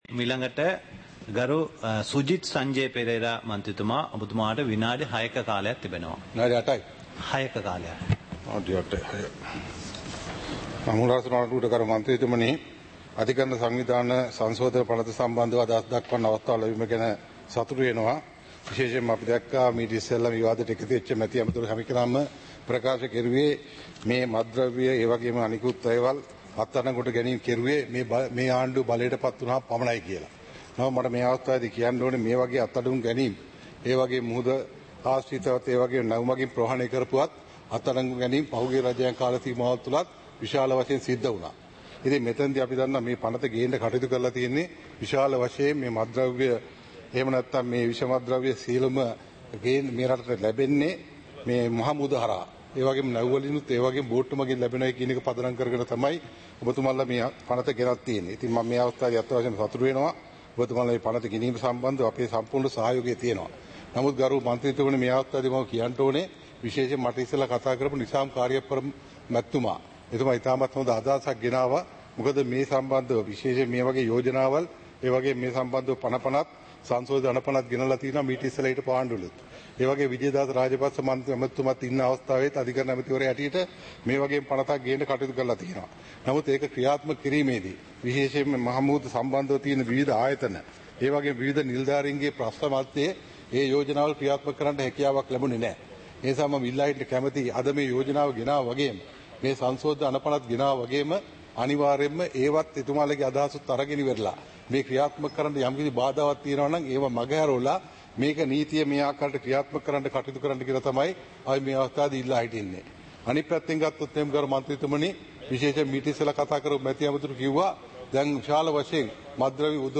சபை நடவடிக்கைமுறை (2026-02-19)